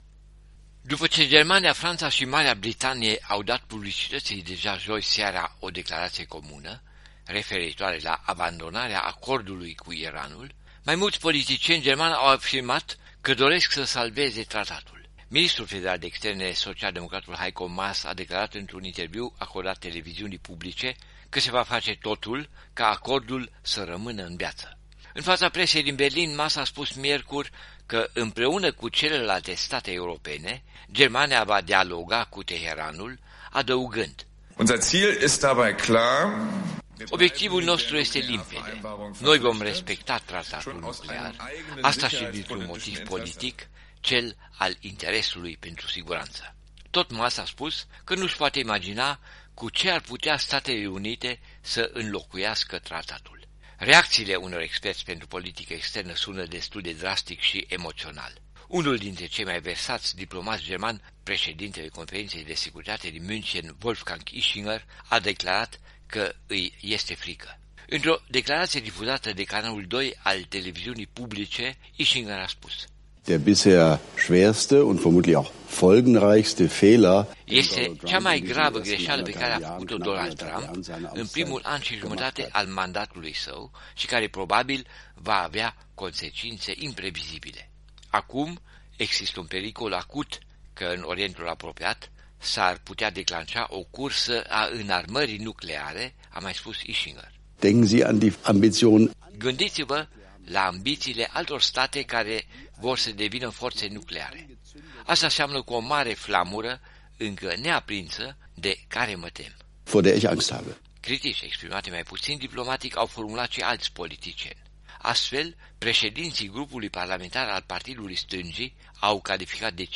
Corespondența zilei de la Berlin